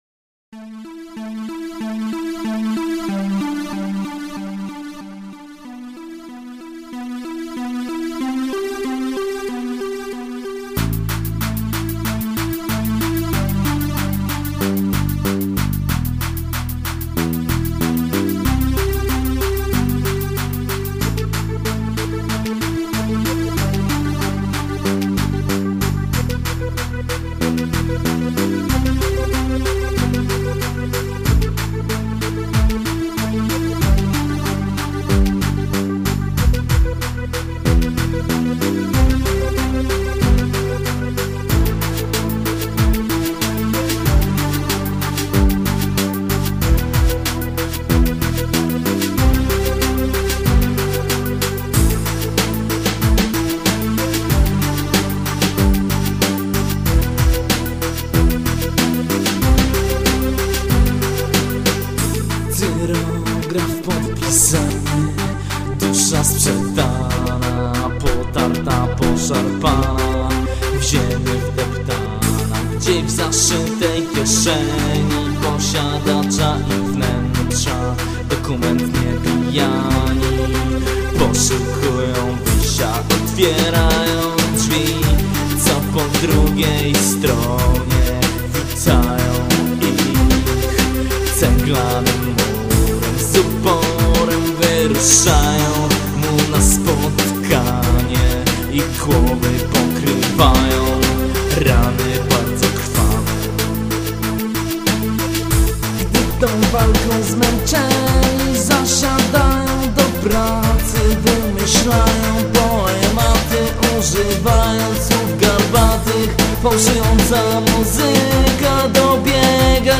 Utworek poważniejszy i o nieco ostrzejszym brzmieniu